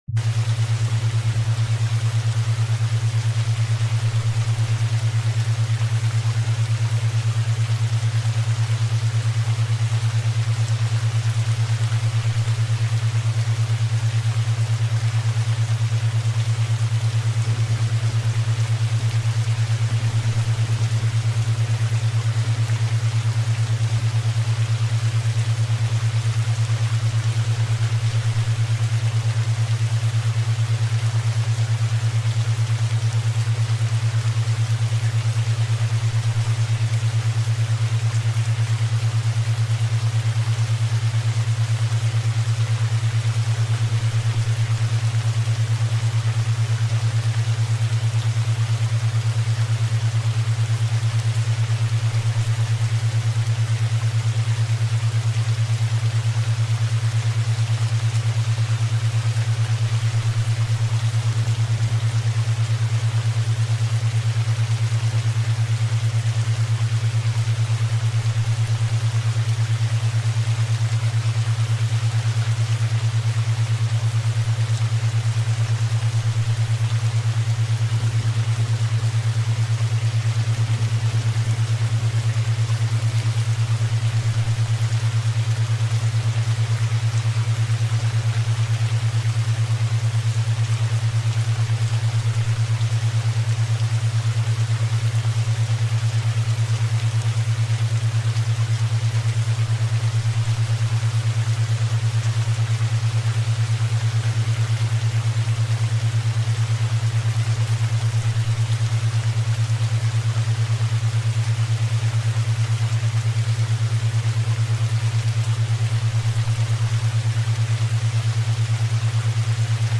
勉強BGM